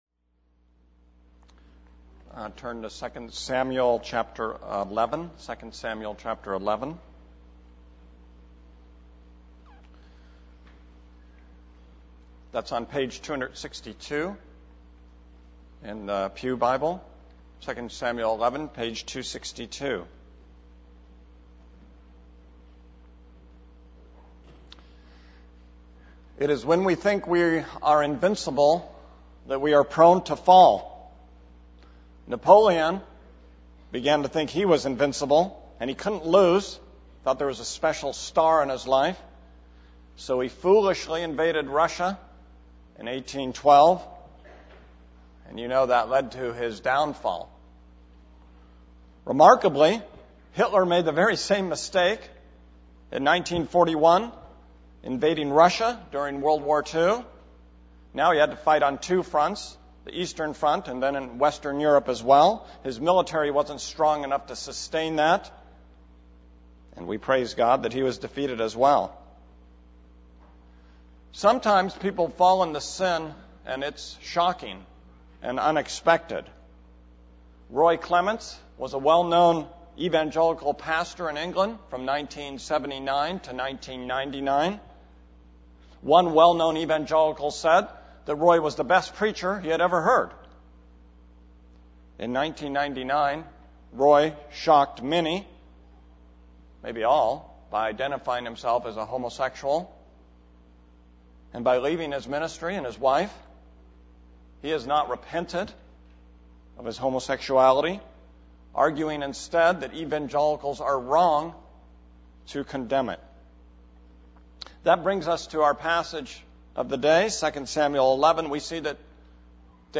This is a sermon on 2 Samuel 11:1-27.